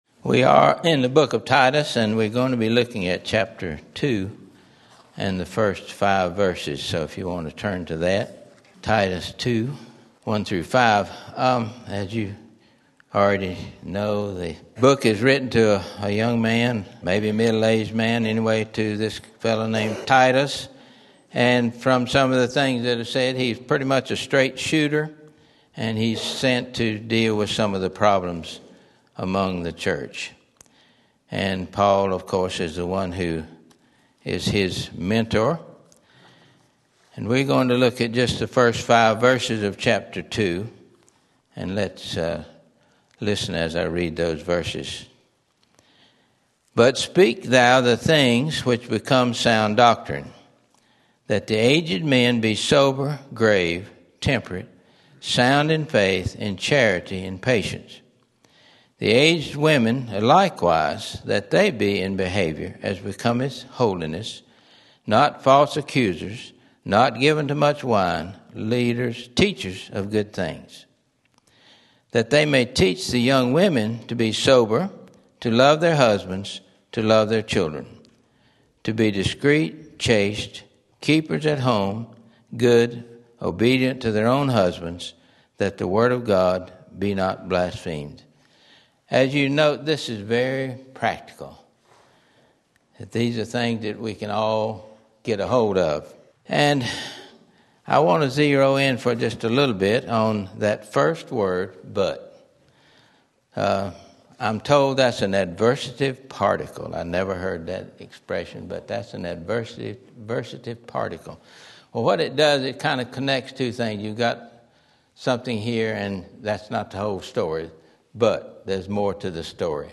Sermon Link
Sunday School